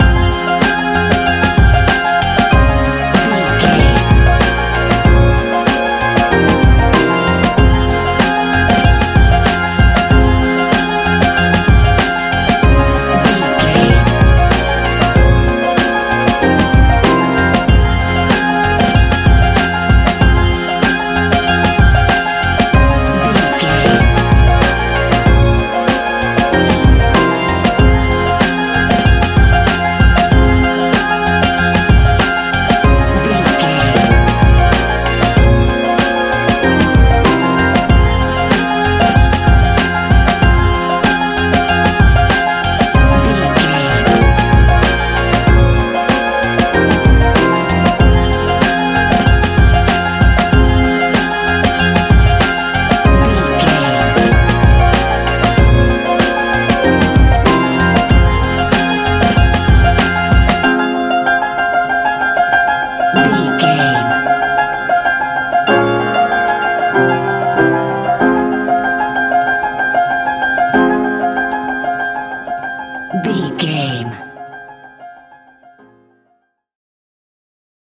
Ionian/Major
F♯
chilled
laid back
Lounge
sparse
new age
chilled electronica
ambient
atmospheric
morphing
instrumentals